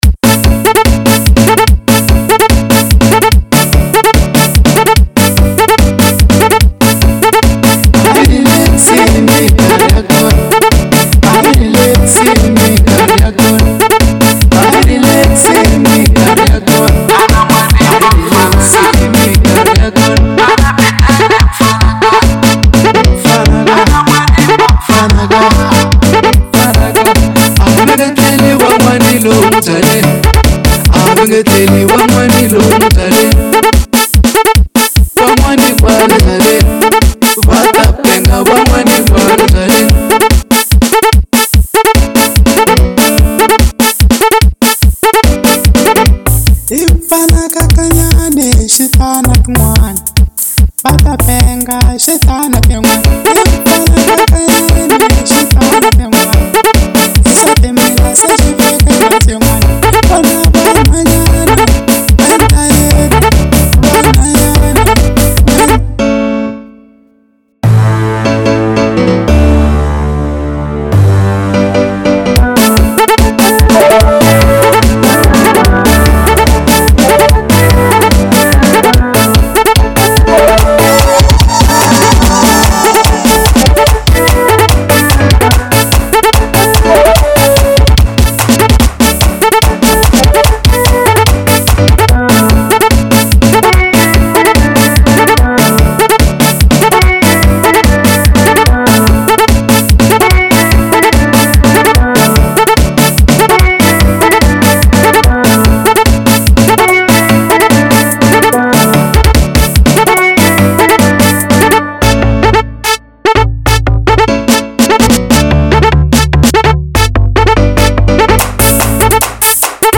04:09 Genre : Xitsonga Size